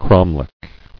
[crom·lech]